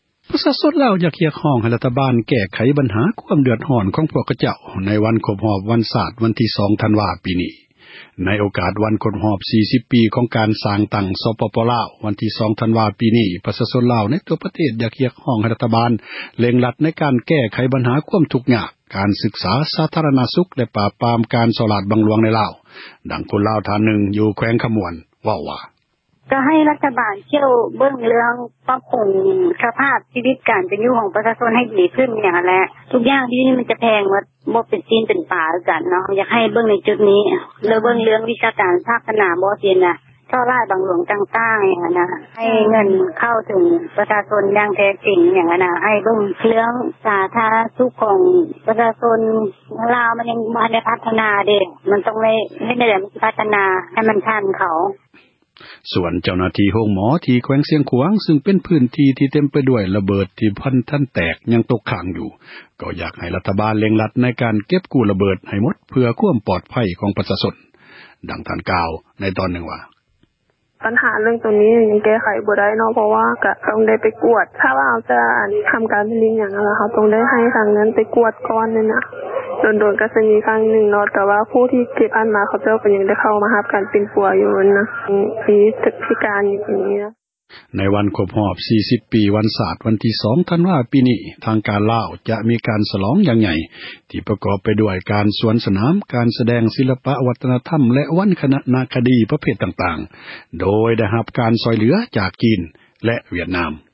ໃນ ໂອກາດ ວັນ ຄອບຮອບ 40 ປີ ຂອງການ ສ້າງຕັ້ງ ສປປລາວ ວັນທີ 2 ທັນວາ ປີ ນີ້, ປະຊາຊົນ ລາວ ໃນທົ່ວ ປະເທດ ຢາກ ຮຽກຮ້ອງ ໃຫ້ ຣັຖບານ ເລັ່ງ ແກ້ໄຂ ບັນຫາ ຄວາມ ທຸກຍາກ, ການສຶກສາ, ສາທາຣະນະສຸກ, ແລະ ປາບປາມ ການ ສໍ້ຣາສ ບັງຫລວງ ໃນລາວ. ດັ່ງ ຄົນລາວ ທ່ານນຶ່ງ ຢູ່ແຂວງ ຄຳມ່ວນ ເວົ້າວ່າ: